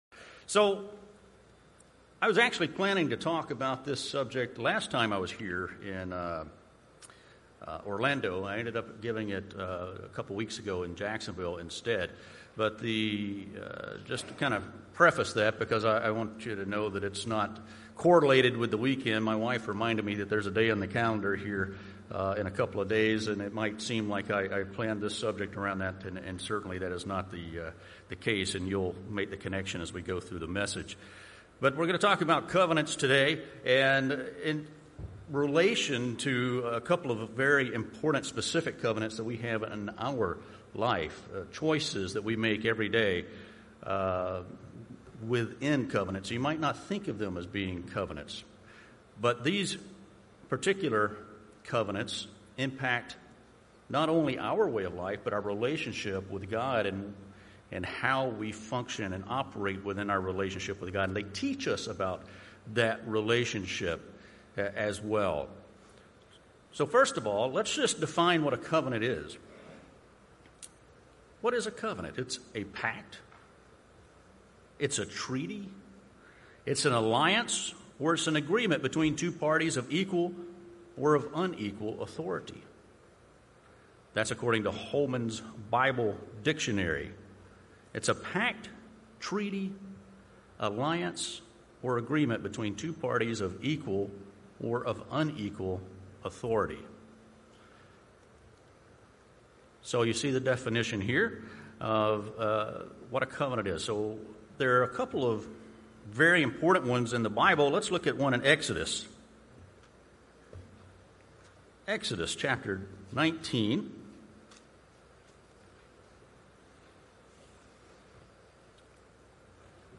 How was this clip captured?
Given in Jacksonville, FL Orlando, FL